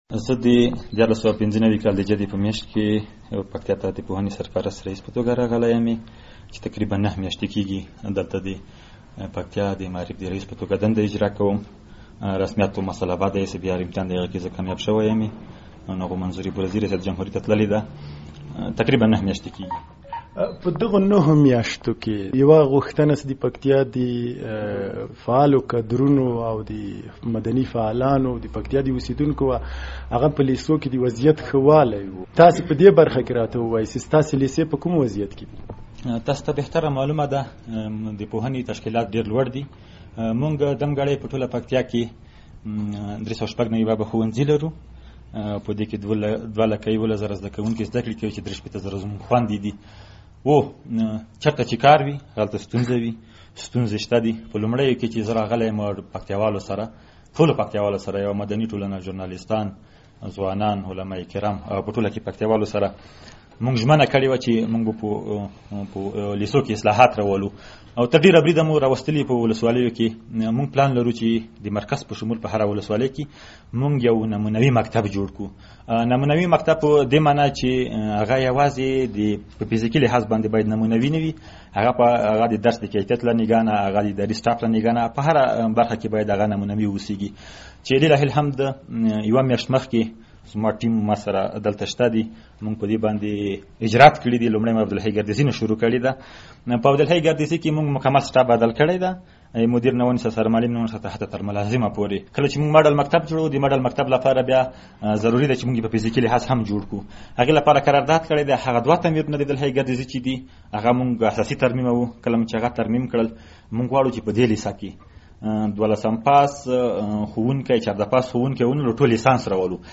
له کوچي ځاځي سره مرکه